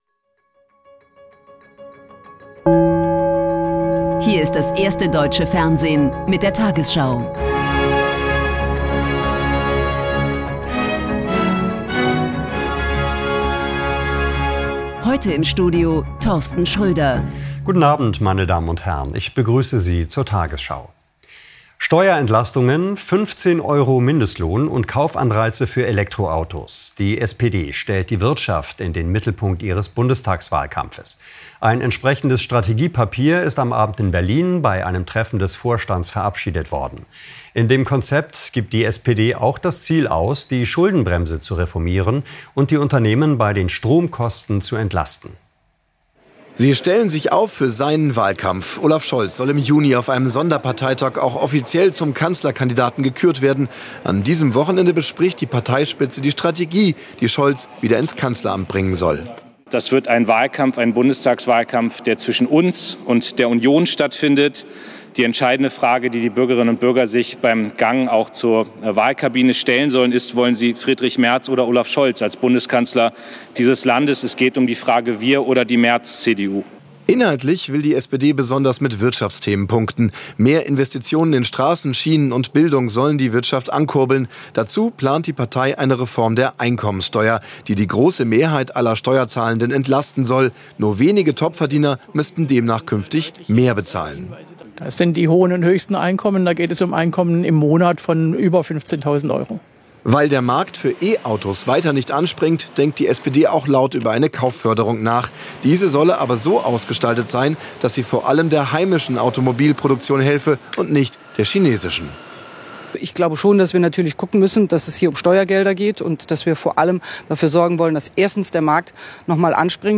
Deutschlands erfolgreichste Nachrichtensendung als Audio-Podcast.